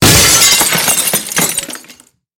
Звуки стекла
Разбитый старинный чайный сервиз